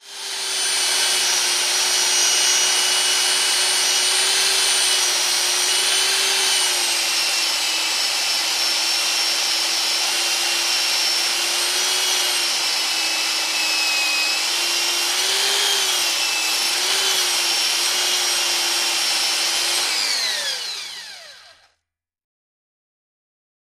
SanderMotorizedSma TE045401
Sander, Motorized, Small Electric Sander Large Table Belt Sander.